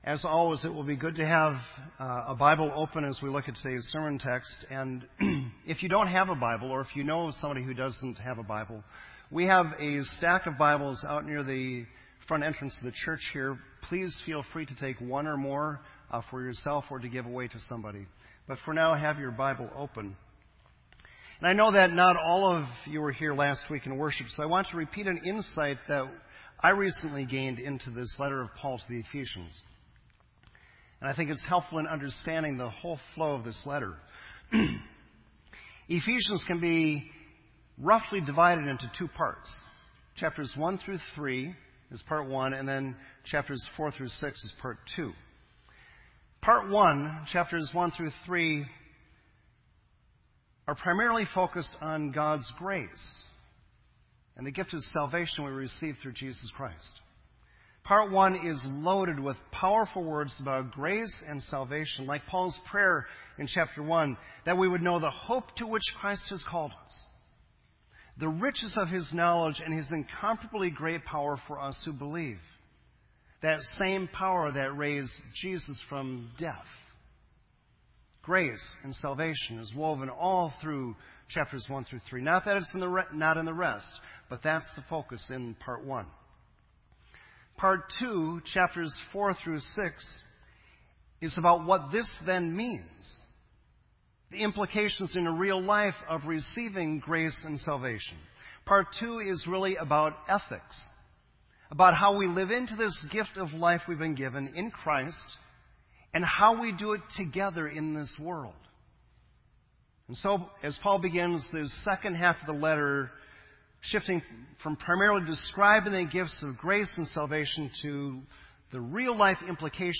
This entry was posted in Sermon Audio on August 8